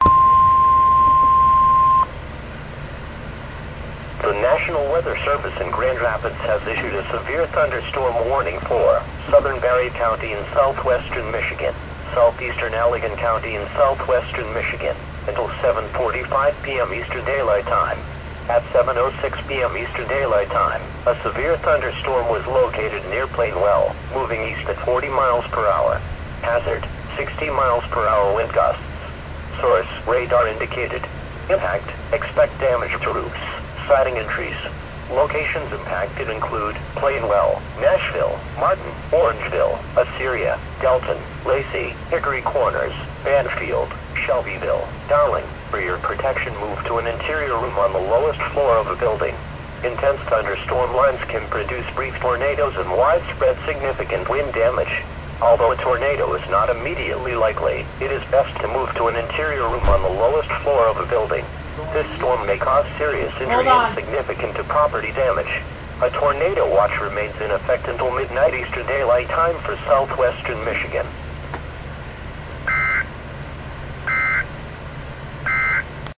这是使用Midland WR100气象收音机和APH Bookport Plus录制的